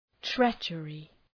{‘tretʃərı}